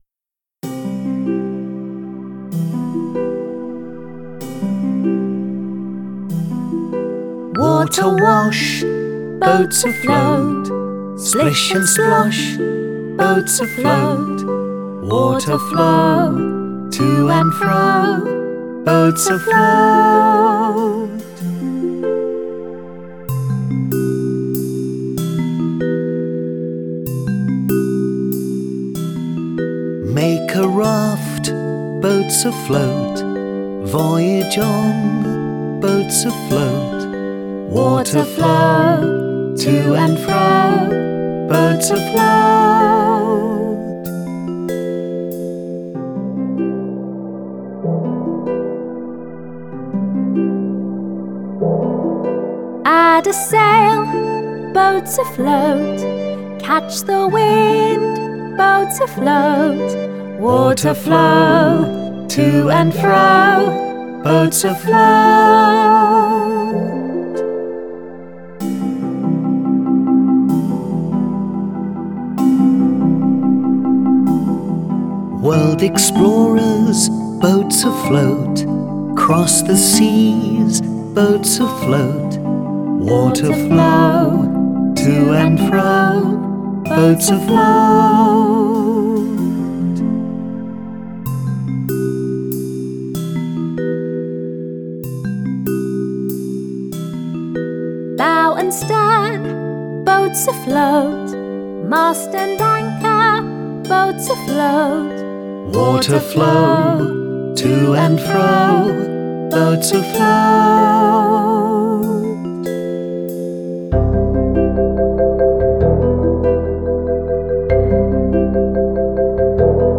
Guide vocal